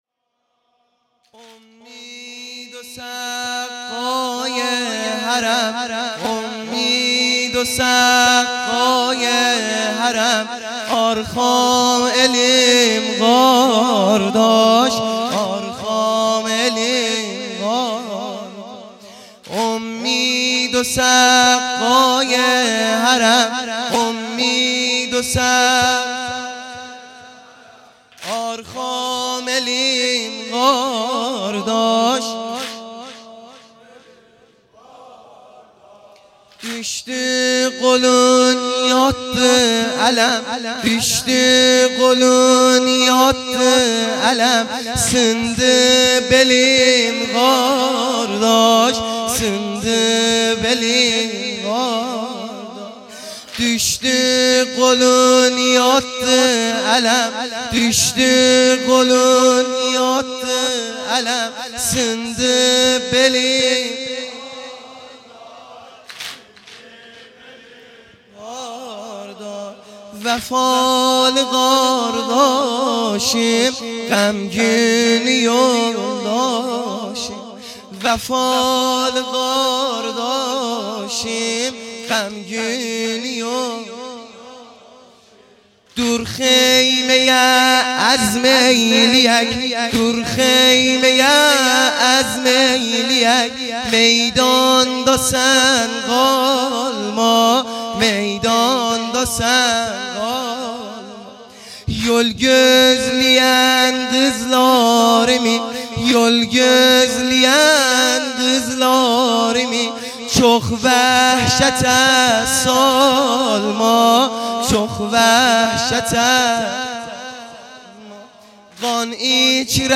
سنگین | امید و سقای حرم
شب نهم محرم الحرام ۱۳۹۶